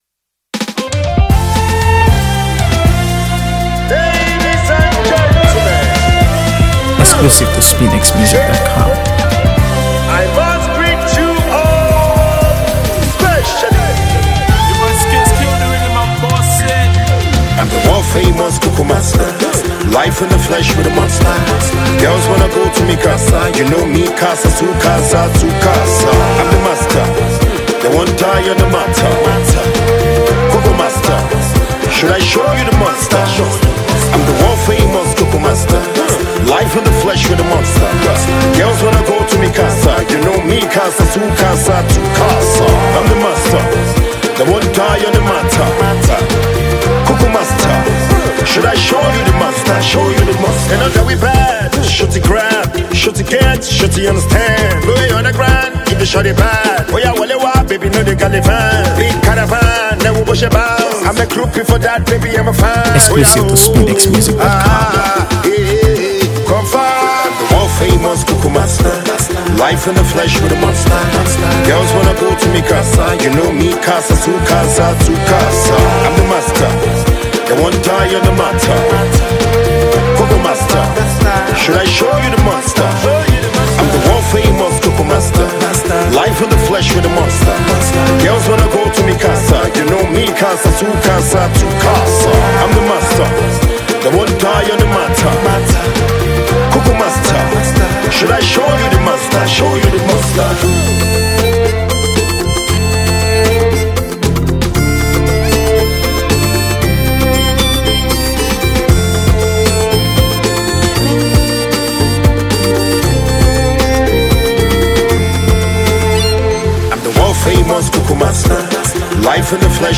AfroBeats | AfroBeats songs
Nigerian afrobeat singer